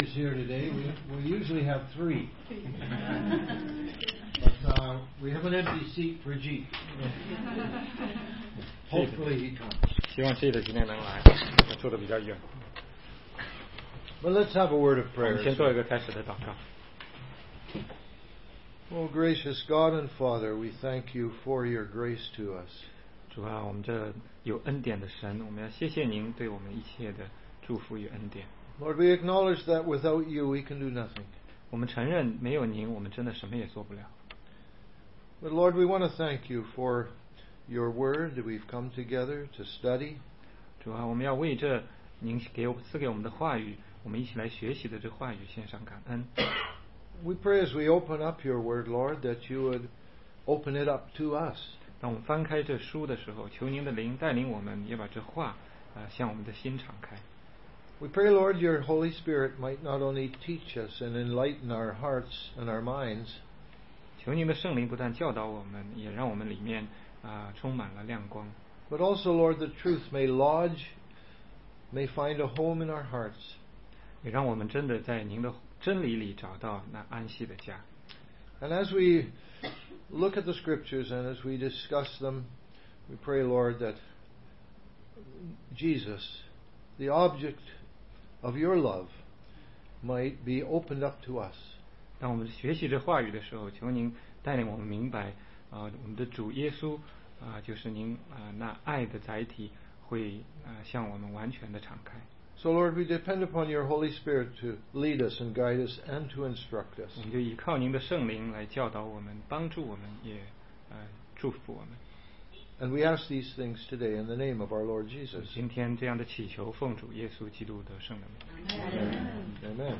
16街讲道录音 - 约翰第七章背景知识--犹太节期及其属灵意义